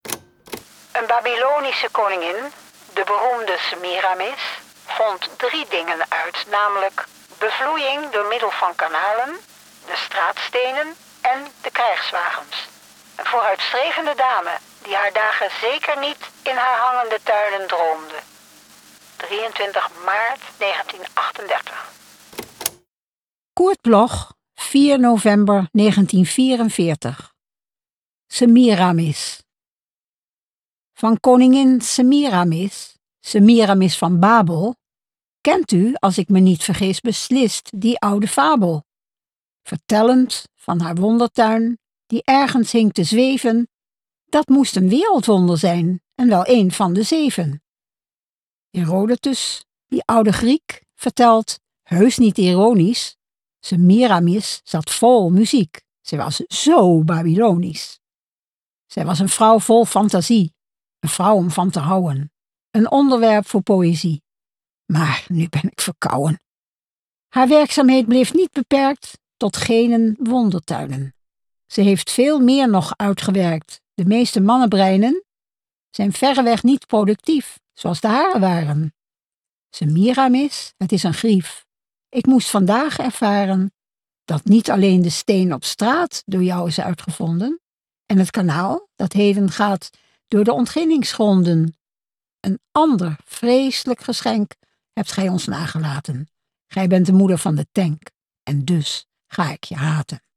vorgetragen